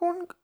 003_short_high.fwonk.wav